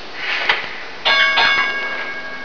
PE Ding Ding sound
PacificElectricDing.WAV